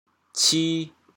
“褫”字用潮州话怎么说？